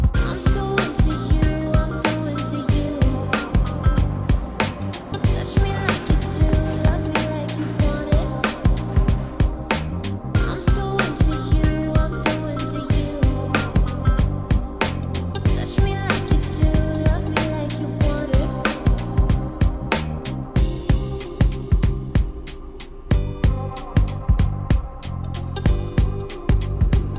женский вокал
атмосферные
Trance
погружающие
Атмосферная музыка